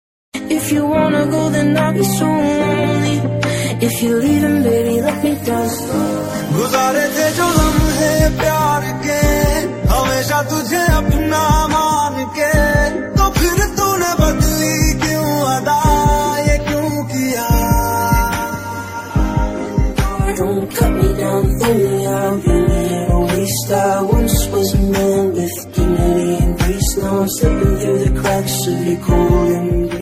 Lofi Remix